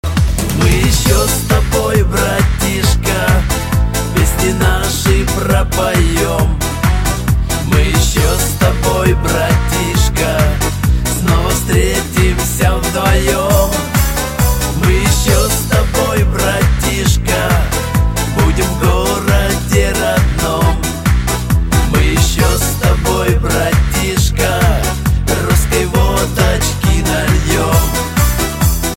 • Качество: 128, Stereo
позитивные
душевные
добрые